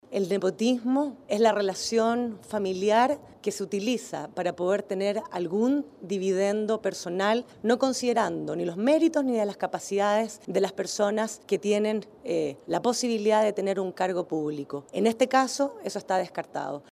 La vocera de Gobierno, Cecilia Pérez, fue la encargada de salir a reiterar que para el Ejecutivo la designación no era comparable con un caso de nepotismo.